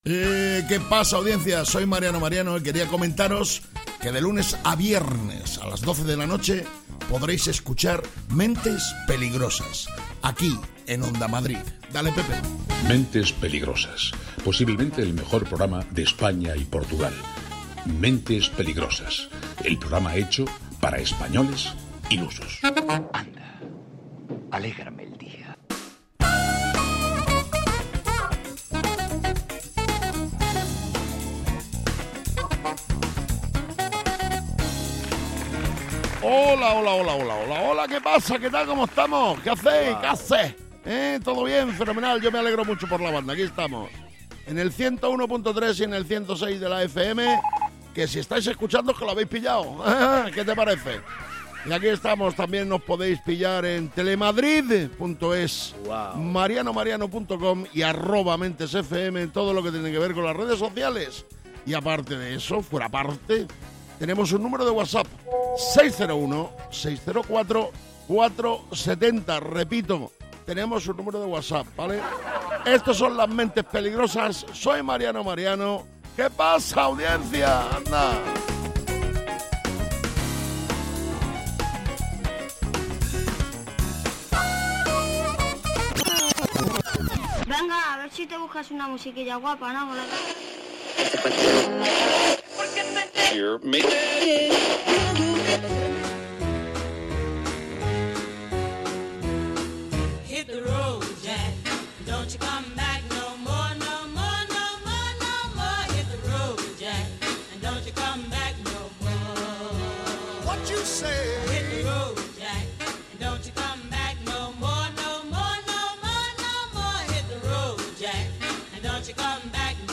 Mentes Peligrosas de Mariano Mariano es un programa de radio en el que cada día se presenta una nueva aventura o no, depende siempre del estado anímico de los participantes en el mismo.
Mentes Peligrosas es humor, y quizás os preguntaréis, ¿y de qué tipo de humor es?